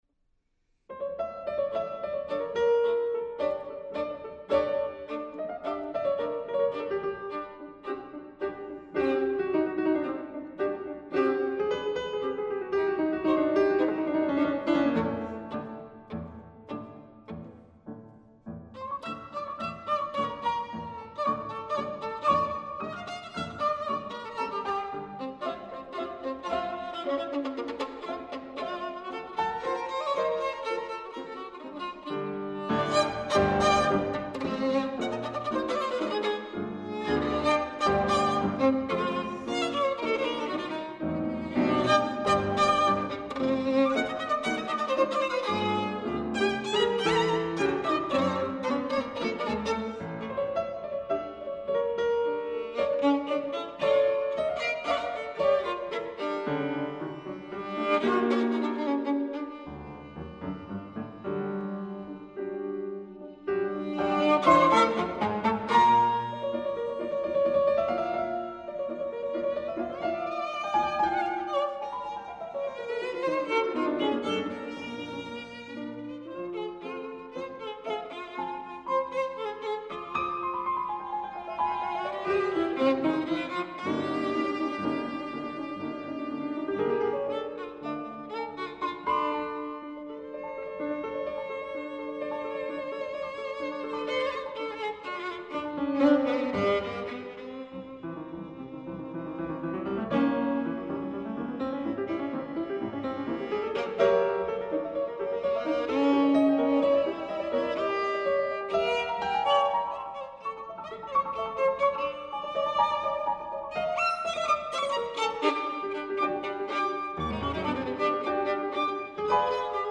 Late Romantic / Contemporary.
fulminant sounds of her youth, both folkloric and classical